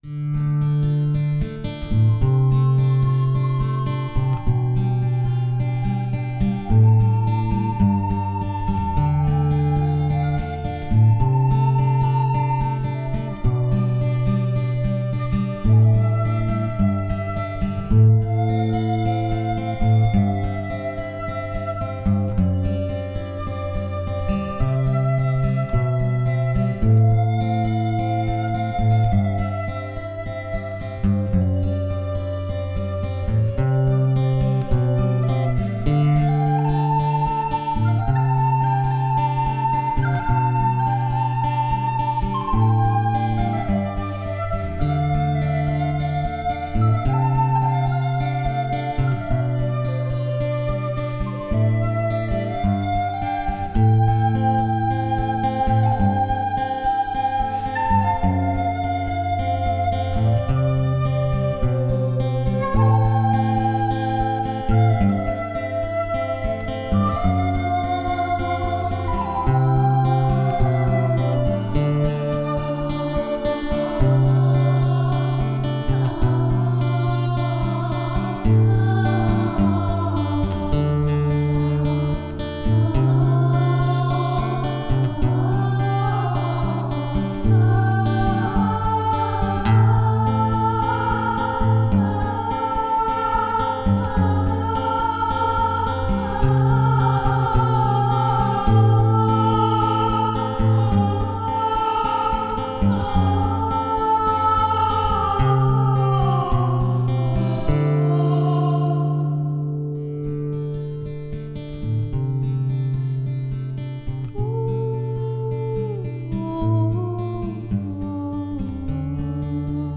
• Жанр: Фолк
Почти инструментальная композиция